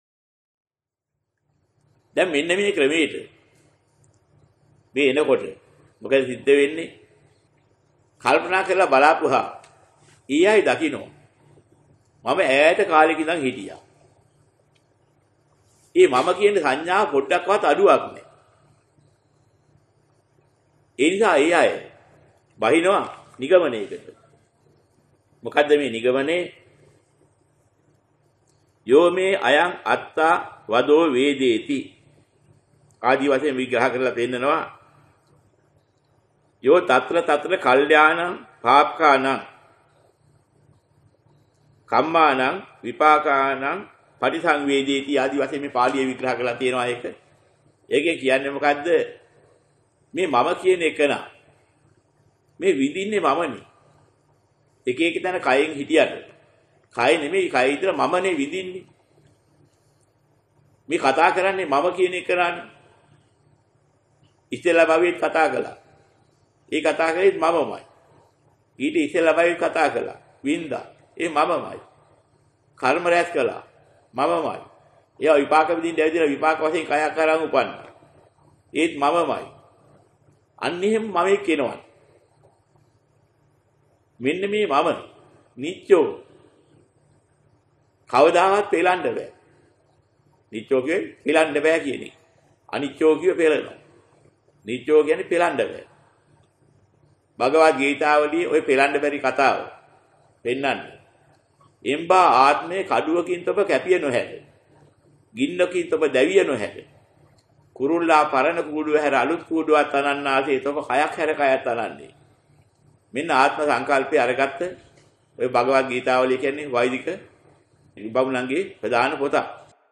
ඇසිය යුතු මූලික දේශනා 7-(9)